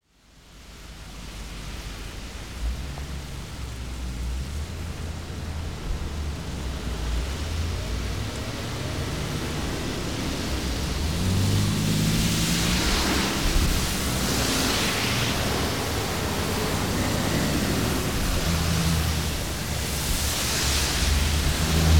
На этой странице собраны звуки автомобильной пробки — гудки машин, шум двигателей и общая атмосфера затора.
Шум машин на мокром асфальте под дождем